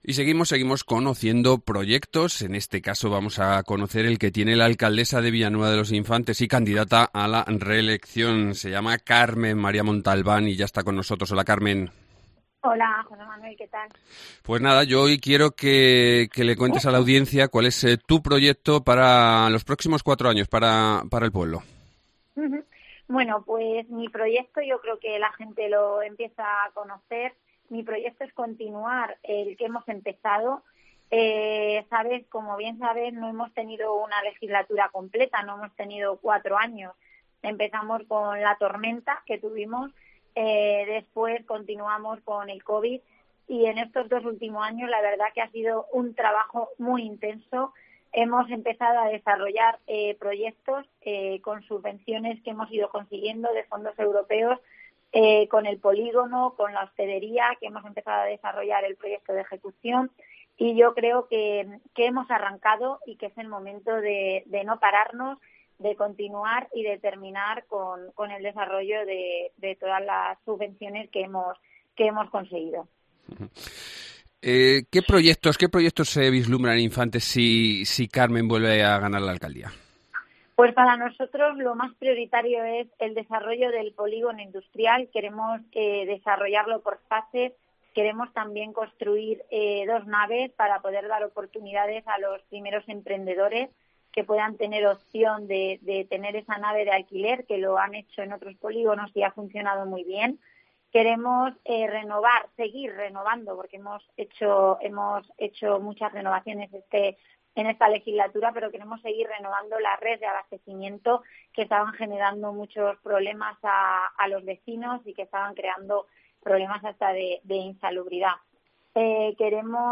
Entrevista
Carmen María Montalbán, alcaldesa de Infantes y candidata a la reelección